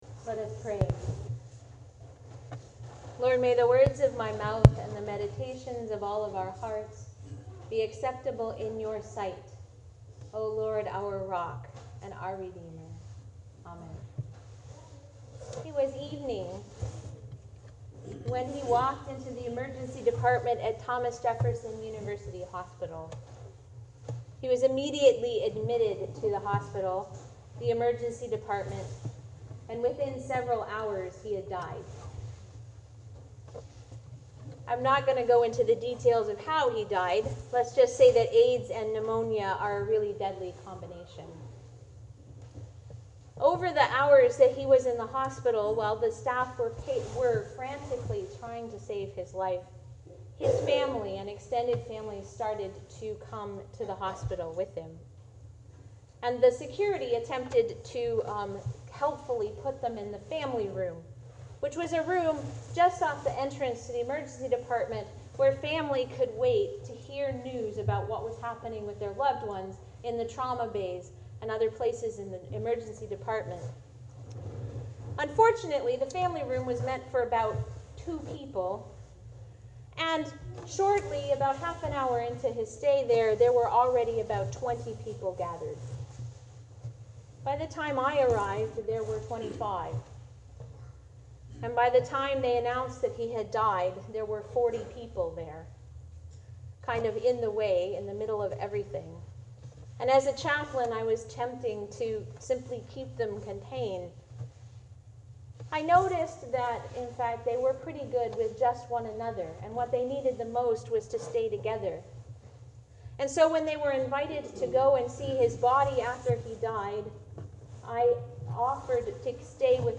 Audio of Sermon:
All Saints Sermon
all-saints-sermon.mp3